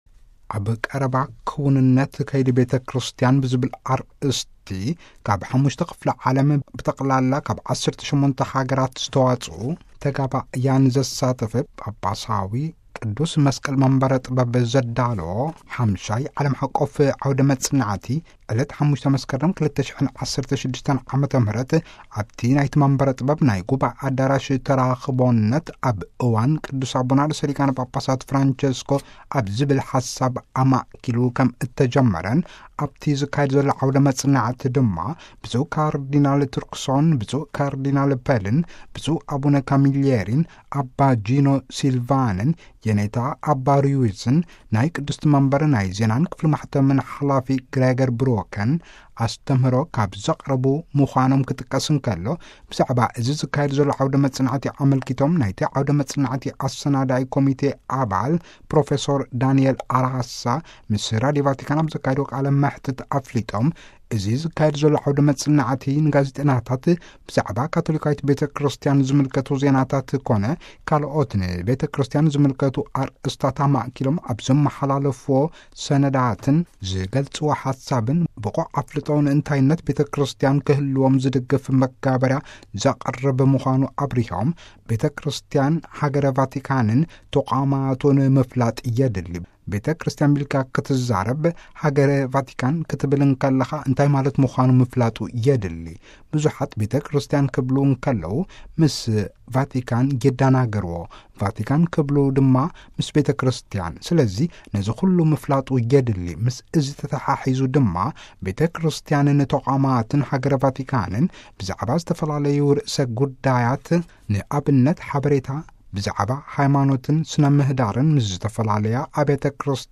ንናይ እናቴ ብፅዕት ተረዛ ዘካልኩታ ቅድስና እዋጅ ዕለት 4 መስከረም 2016 ዓ.ም. ኣብ ቅርዓት ቅዱስ ጴጥሮስ ዝዓረገ መስዋዕተ ቅዳሴን ከይድታቱን ኩሉ ብዓለም ሓቖፍ ደረጃ ዓቢይ ሽፋን ተዋሂብዎን ብዙሕ ትንተናን ክቐርብን ዜናታት ተመሓላሊፉን እዩ። ሕርየት ኣርእስተ ሊቃነ ጳጳሳት ብዓለም ሓቆፍ ደረጃ ዝወሃቦ ኣዚዩ ዝዓበየ ሽፋን ኩሉ ዝፈልጦ እዩ። ብሓጺሩ ድማ ቤተ ክርስቲያን ንናይ ዓለም መራኸቢ ብዙሃን ስሕበት እያ ክበሃል ይከኣል። ቅዱስ ኣቦና ር.ሊ.ጳ. ብዛዕባ ዓበይቲ ኣርእስታት ዓለም ፖለቲካውን ማሕበራውን ሰብኣውን ኤኮኖሚያውን ካልኦትን ዝኣመሰሉ ጉዳያት ዝህብዎ ሓሳብን ዘቕርብዎም ኣስተህሮታት ብዙሕ ሽፋን እዩ ዝወሃቦ ንብዙሓት መራሕቲ ሃገራትን ሕዝቢ ዓለምን ድማ መወከሲ ሓሳብን ምዕዶን መርሕ ሓሳብ ኰይኑ ይረአይ። ስለዚህ ቤተ ክርስቲያን ኩለ መናኣ ምፍላጥ የድሊ እቲ ዓውደ መጽናዕቲ ድማ ነዚ እዩ ዘብርህ እንክብሉ ዘካድዎ ቃለ መሕትት ኣጠቓሊሎም።